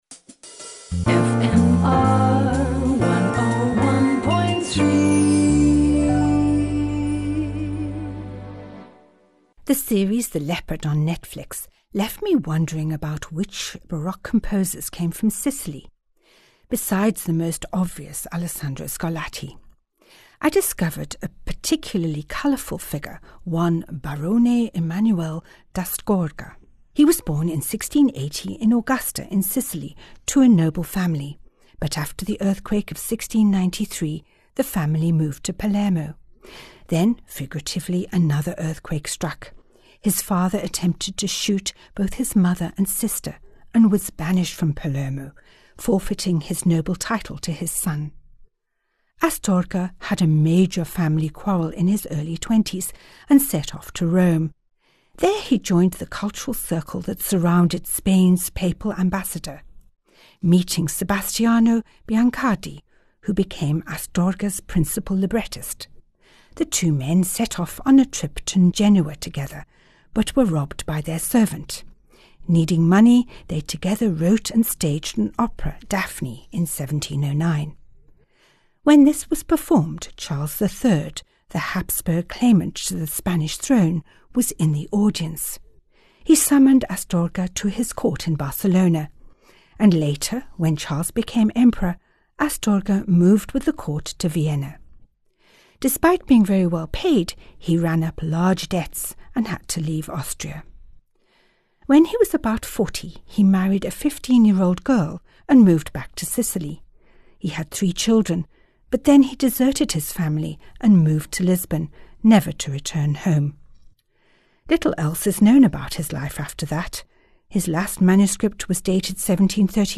Each weekly Bon Bon is accompanied by a piece of Baroque music which ties in with the story.